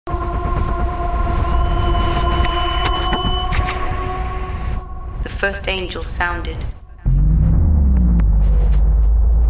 original soundtrack